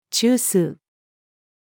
中枢-female.mp3